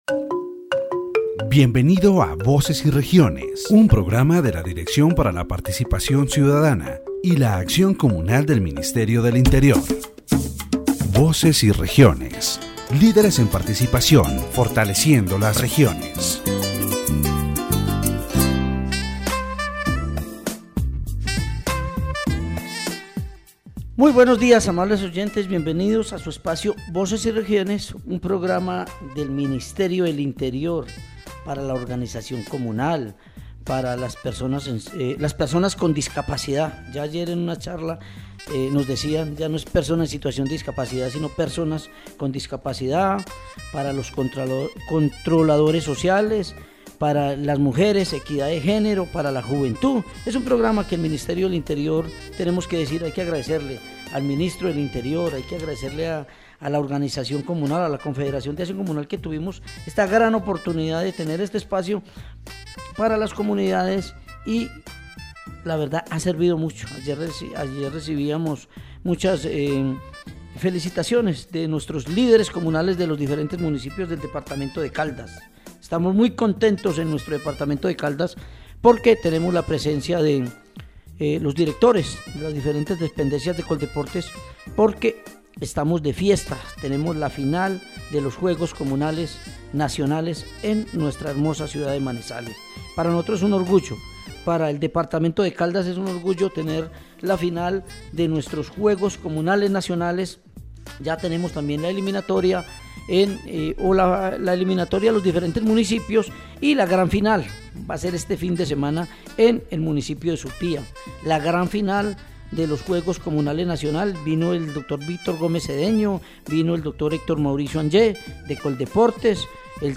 In addition, interviews are included with various officials and community leaders who express their support and commitment to the games and the community.